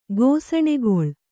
♪ gōsaṇegoḷ